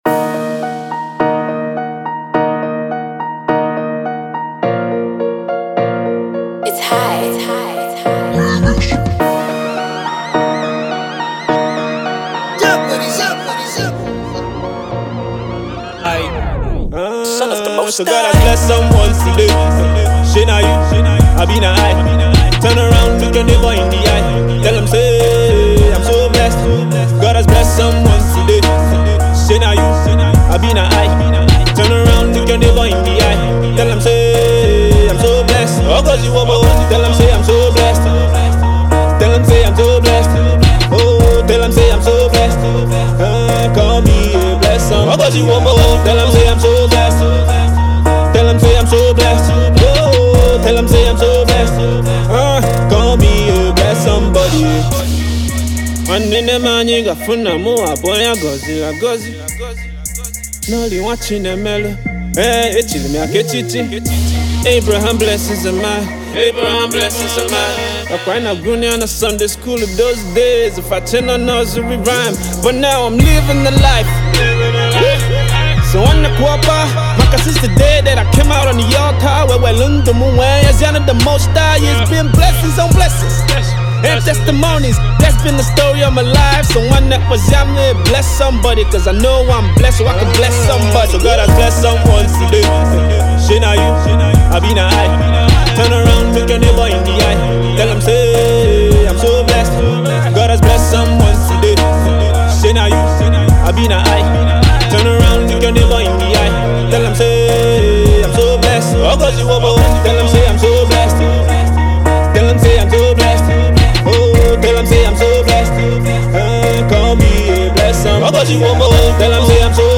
cover version
trap hit